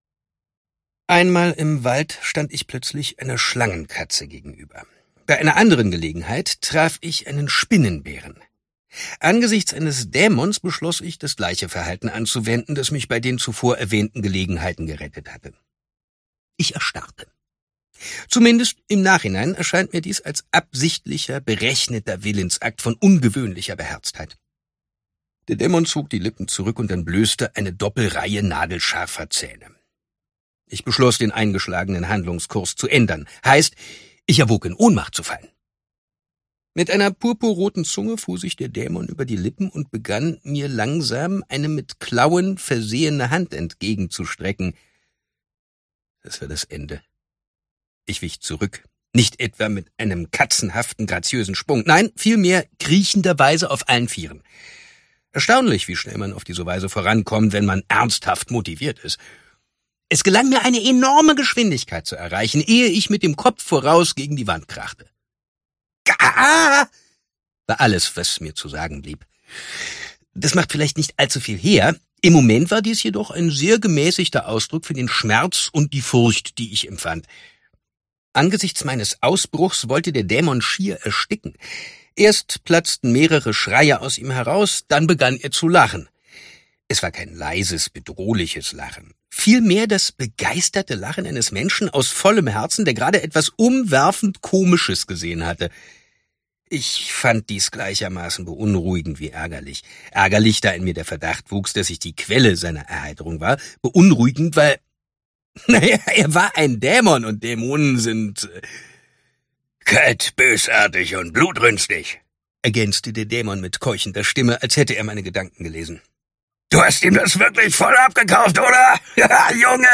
Ausgabe: Ungekürzte Lesung